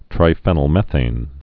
(trī-fĕnəl-mĕthān, -fēnəl-)